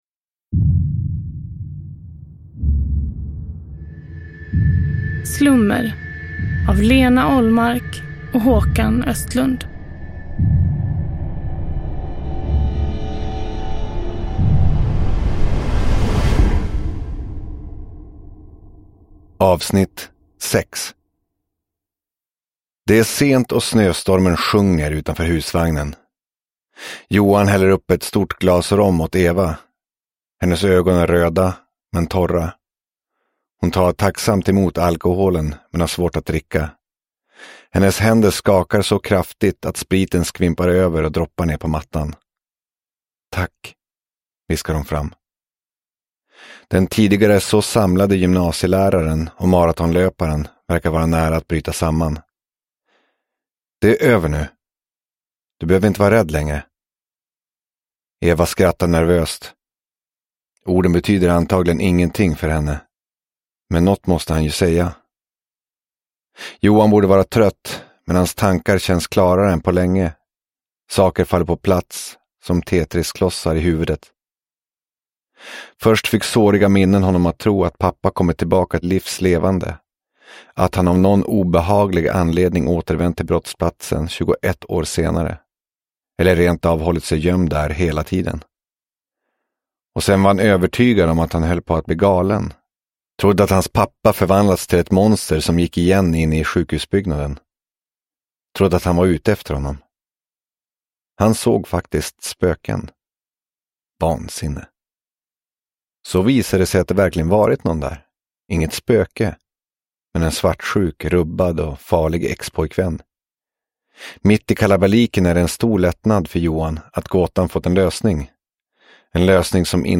Slummer - Del 6 – Ljudbok – Laddas ner